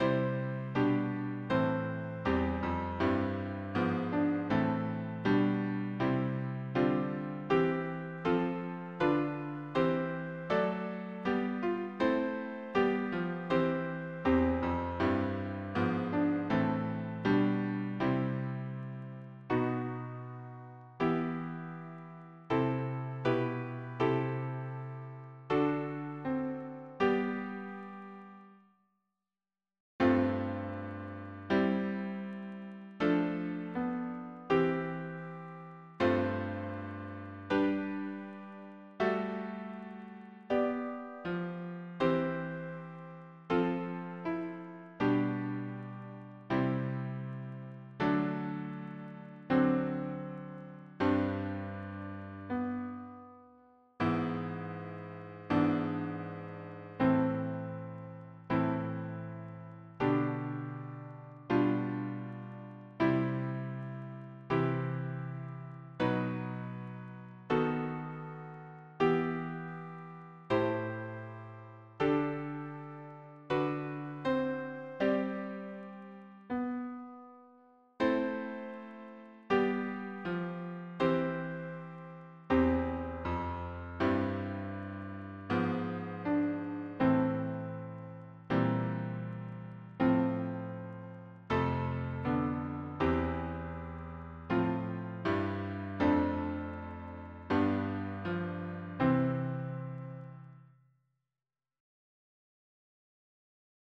Information about the hymn tune ECCLESIA (Terry).
Key: C Major